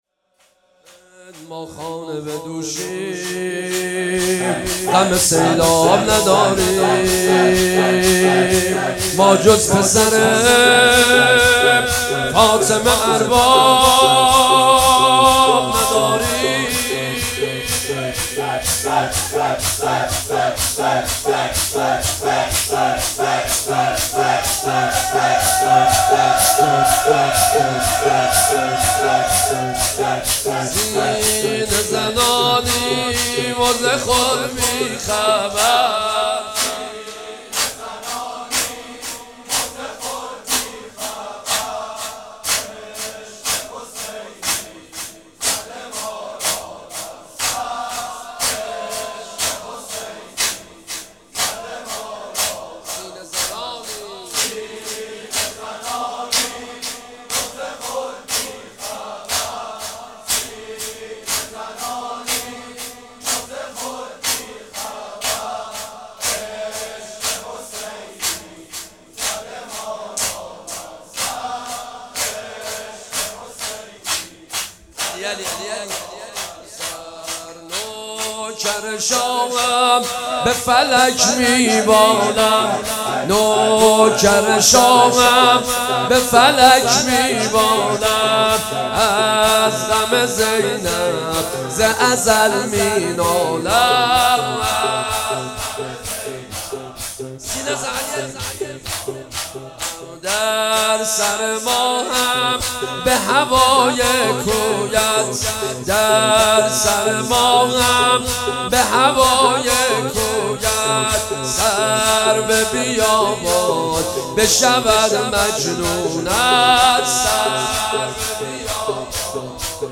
فاطمیه 96 - شور - ما خانه بدوشیم غم سیلاب نداریم